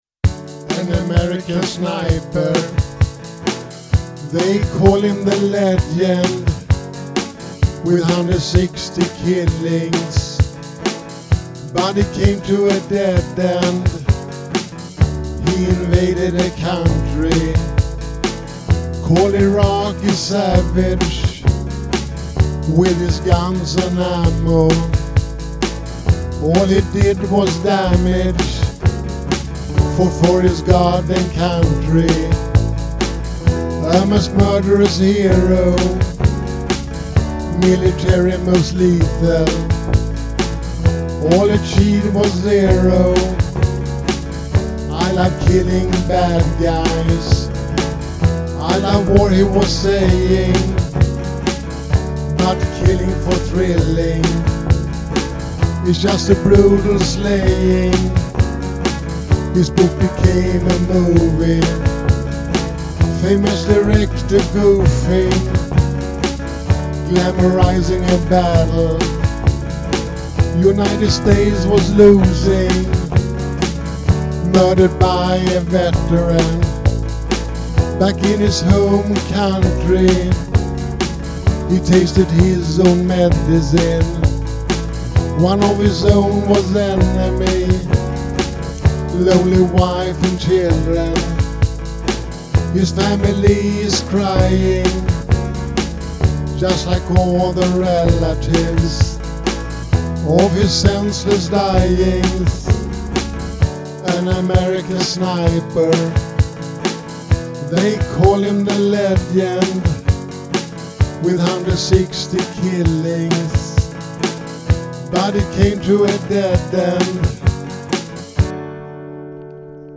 protestsång